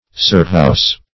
cirrhous - definition of cirrhous - synonyms, pronunciation, spelling from Free Dictionary Search Result for " cirrhous" : The Collaborative International Dictionary of English v.0.48: Cirrhous \Cir"rhous\, a. See Cirrose .
cirrhous.mp3